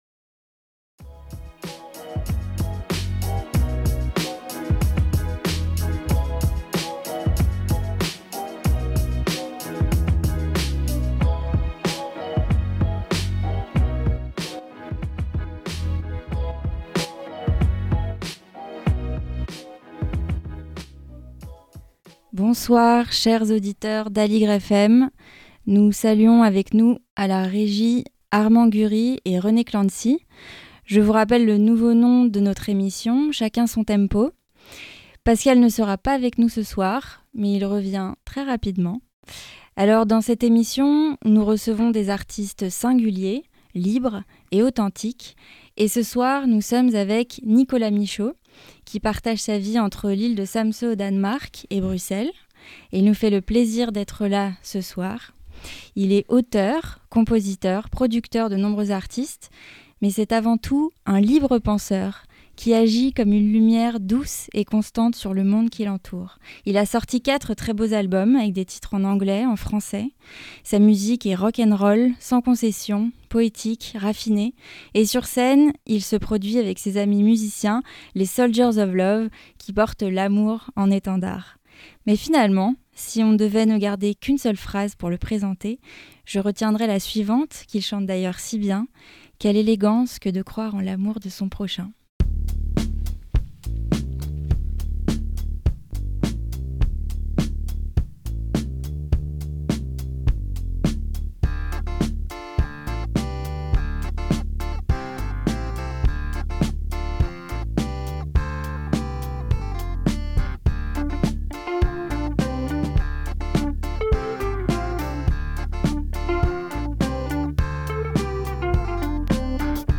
Portrait radiophonique